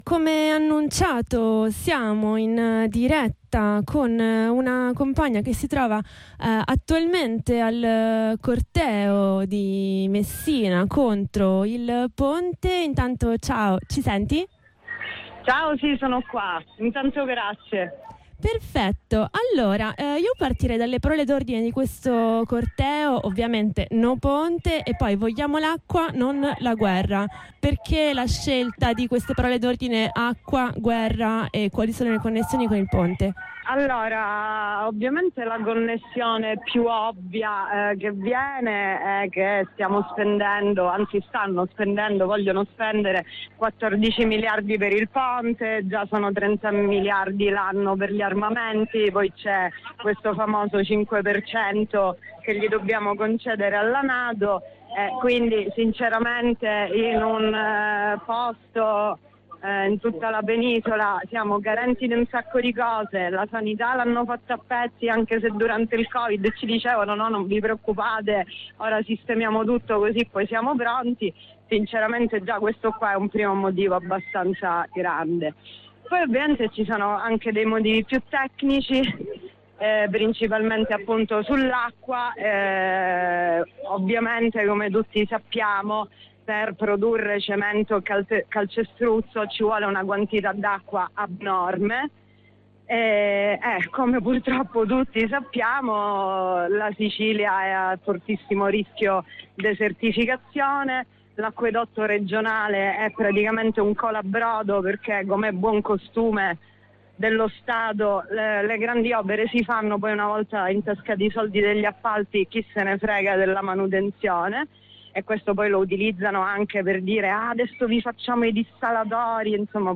Abbiamo sentito una compagna di Messina in diretta dal corteo.
corteo_noponte.mp3